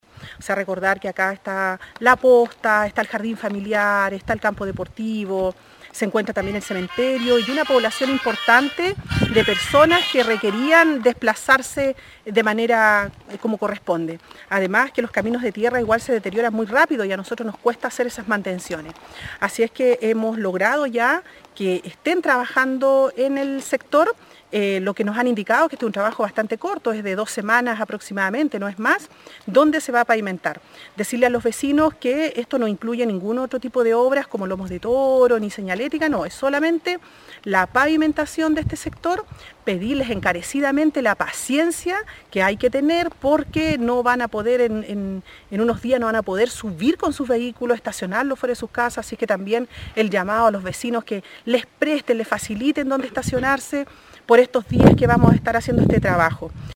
La alcaldesa de Río Hurtado Carmen Juana Olivares visitó el camino de Serón junto con las vecinas del sector y se refirió a estos importantes trabajos que está ejecutando Vialidad en la zona.